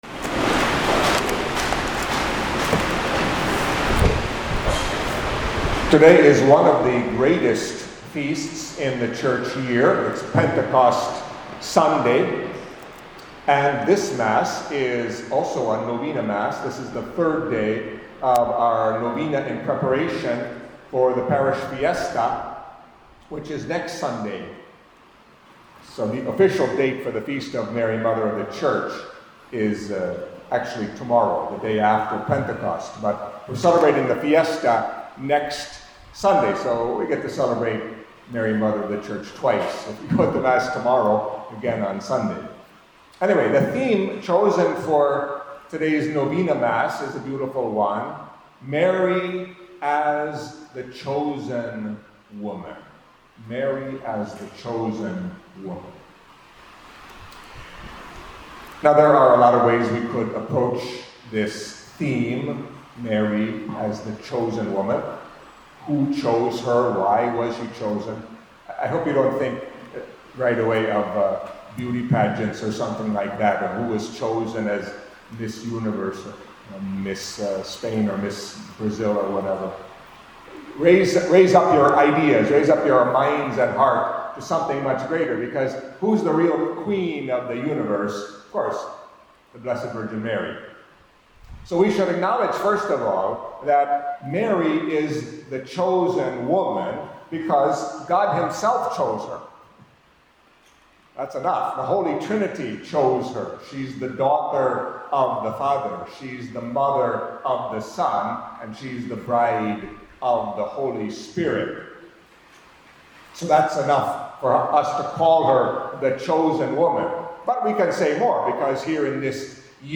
Catholic Mass homily for Pentecost Sunday